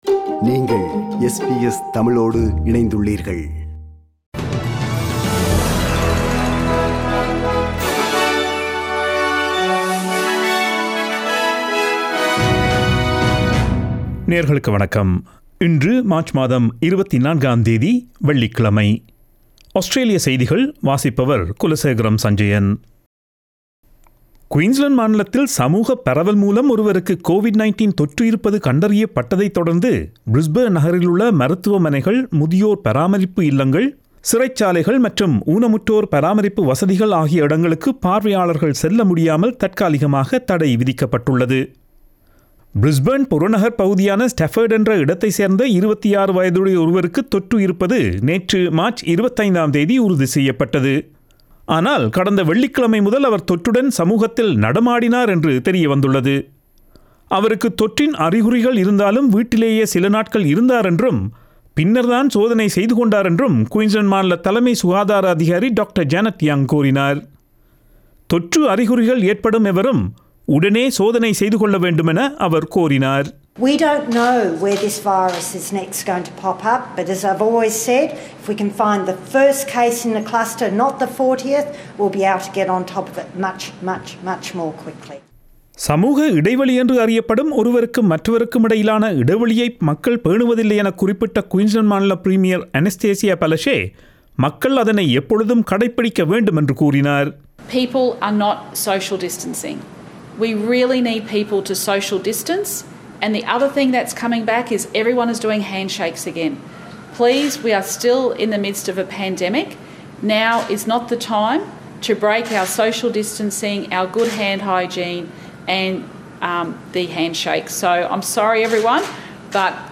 Australian news bulletin for Friday 26 March 2021.